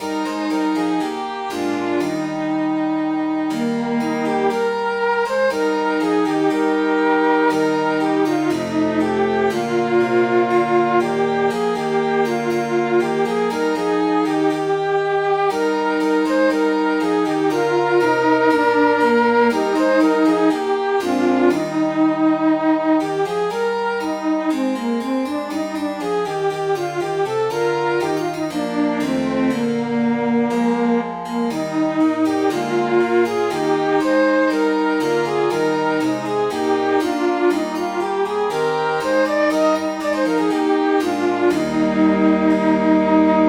Midi File, Lyrics and Information to My Days Have Been So Wondrous Free